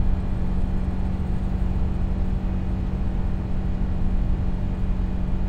Subaru Impreza GD WRX 12 - INT - 6k rpm loop stereo.wav